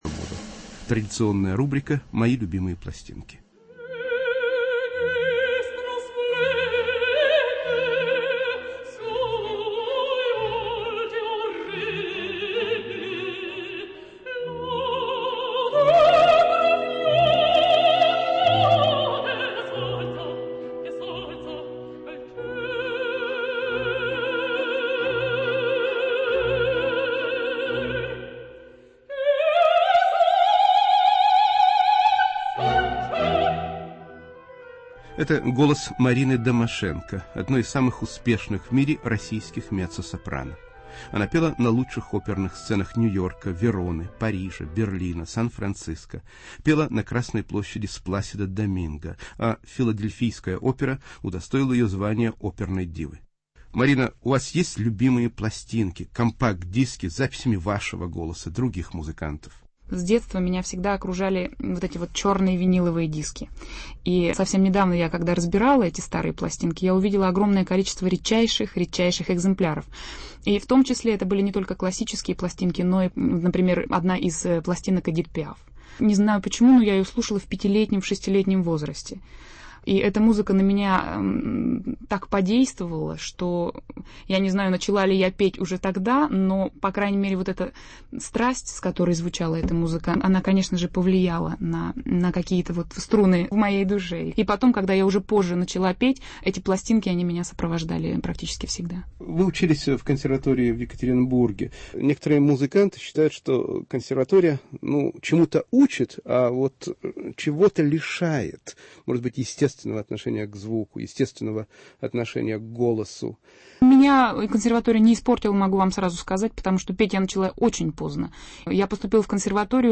Меццо-сопрано Марина Домашенко